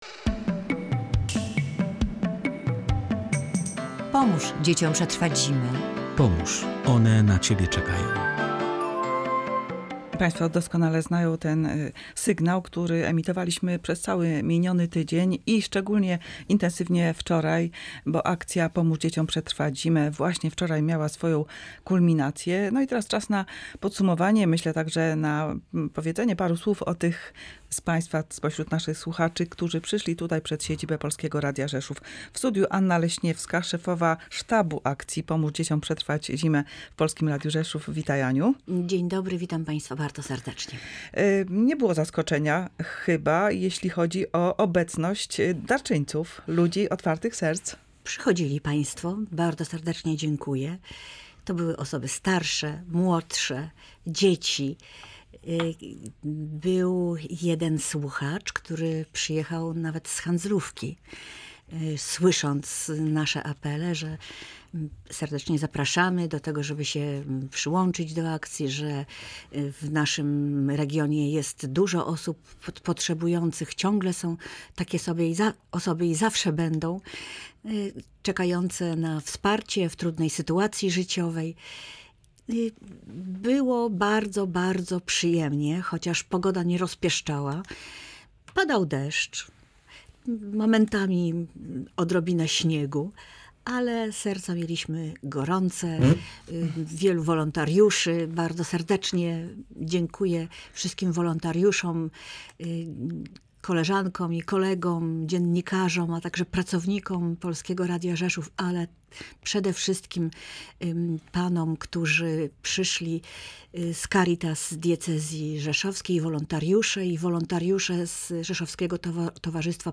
Szczegóły w rozmowie